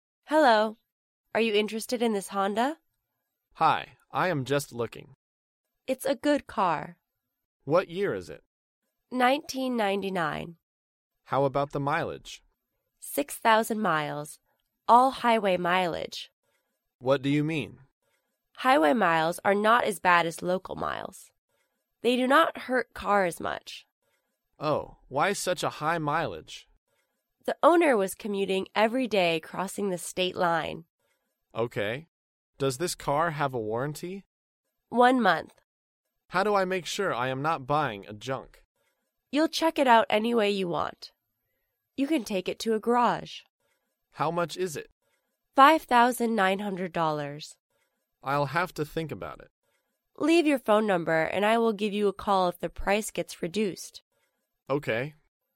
在线英语听力室高频英语口语对话 第485期:买二手车(2)的听力文件下载,《高频英语口语对话》栏目包含了日常生活中经常使用的英语情景对话，是学习英语口语，能够帮助英语爱好者在听英语对话的过程中，积累英语口语习语知识，提高英语听说水平，并通过栏目中的中英文字幕和音频MP3文件，提高英语语感。